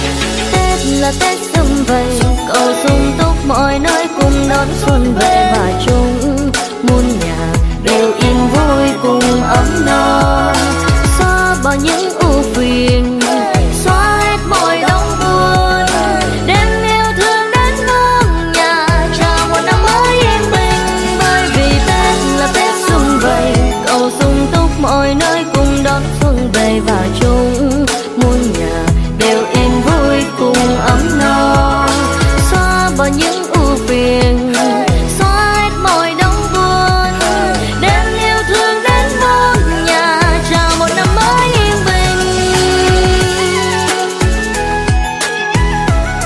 Nhạc chuông 3 lượt xem 13/03/2026